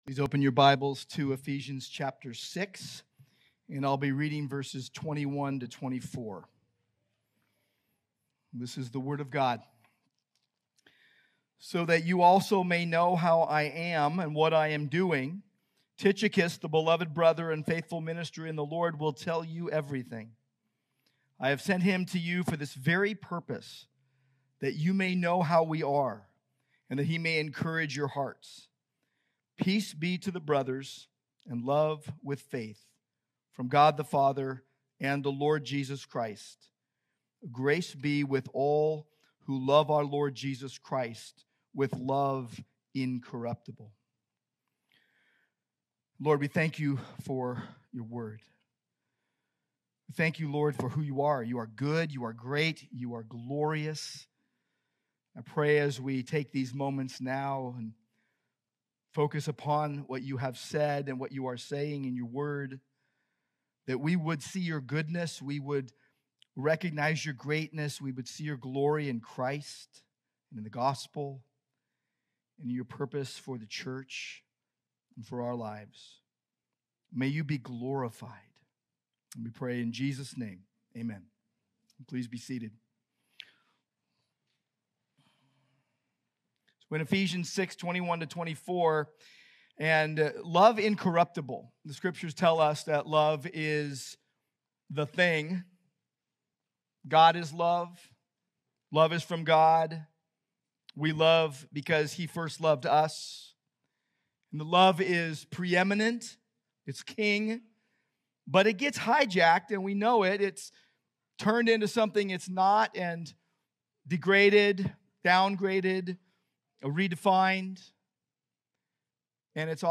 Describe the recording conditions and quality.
Sermons from Grace Church of Orange: Orange, CA